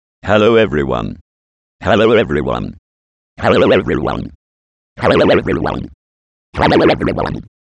Modulate one waveform/track with another (FM synthesis)